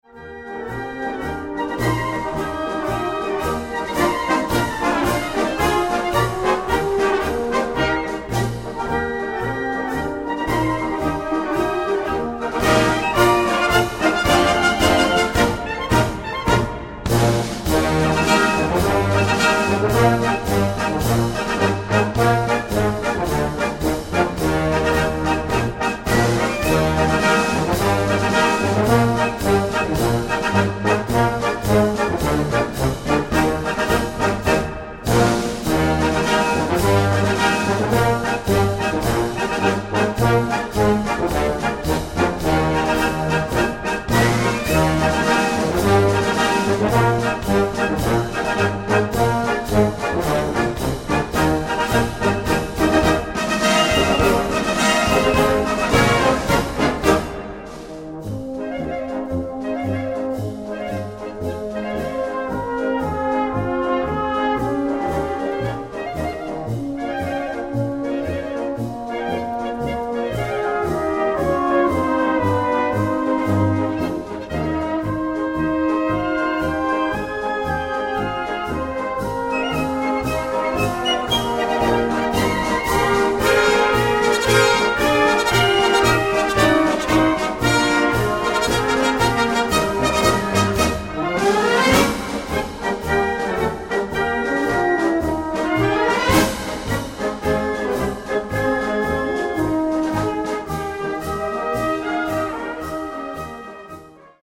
Gattung: Marsch
3:13 Minuten Besetzung: Blasorchester Zu hören auf